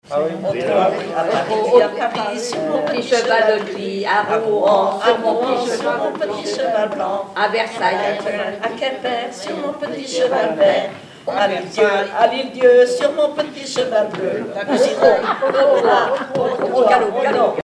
formulette enfantine : sauteuse
Chansons, formulettes enfantines
Pièce musicale inédite